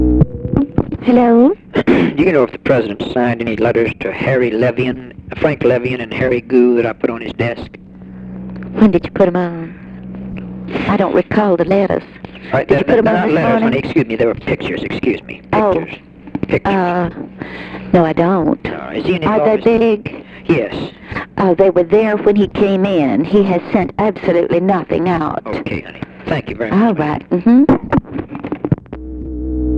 Conversation with JACK VALENTI
Secret White House Tapes